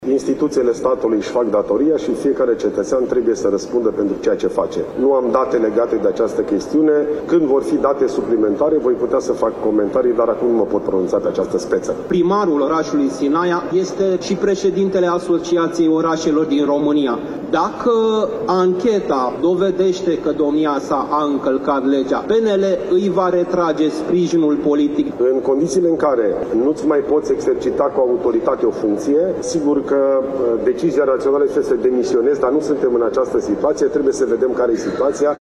Președintele Partidului Național Liberal, Ilie Bolojan a fost întrebat de jurnaliști cum comentează aceste suspiciuni și dacă îi va retrage lu Vlad Oprea sprijinul politic în cazul în care va fi pus sub învinuire: